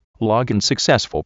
mike.login.successful.wav